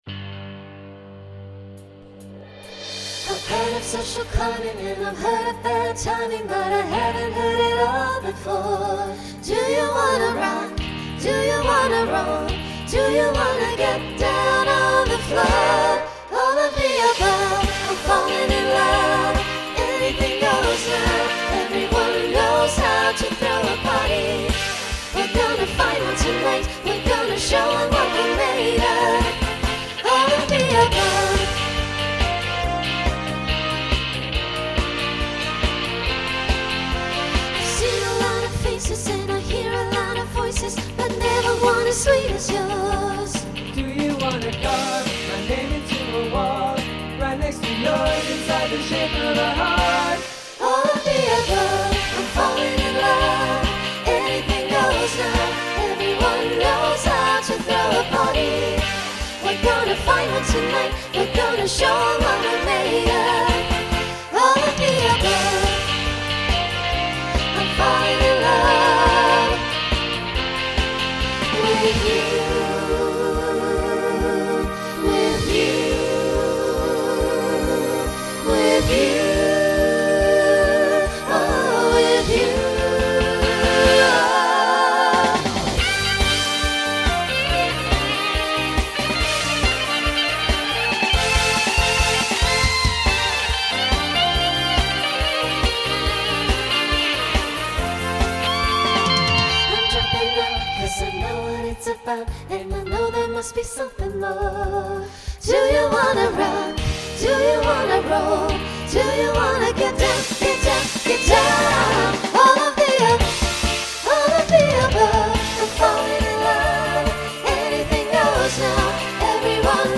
SATB Instrumental combo Genre Rock